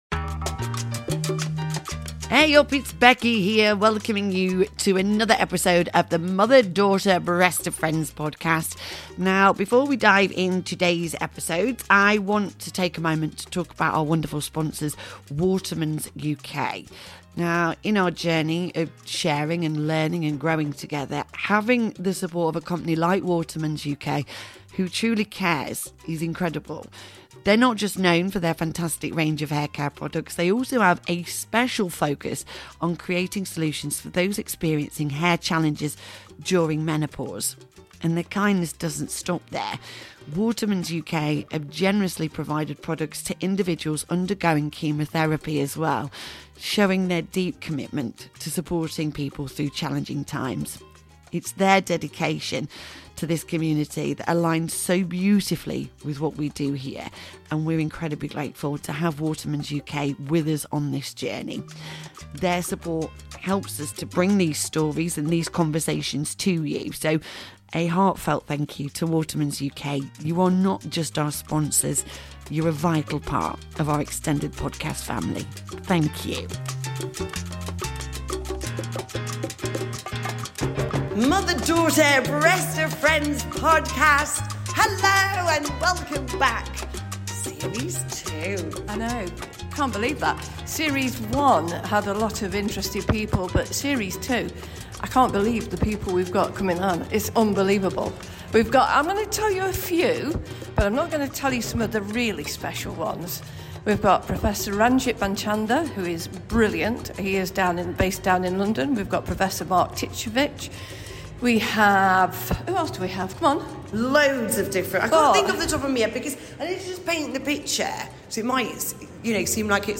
Amidst the backdrop of soul-stirring performances by the Sheffield Cancer Choir and the amazing RMC Academy students, our special guests share their insights and experiences, enriching our understanding of the challenges and triumphs in the fight against cancer.